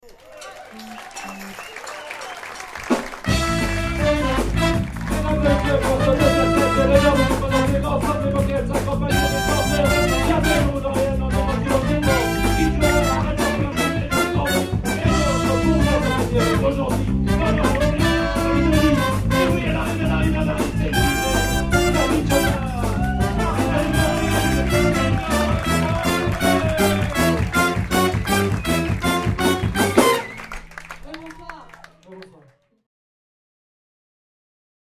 Big  Band - Rythm'n Blues
THEME INTRODUCTION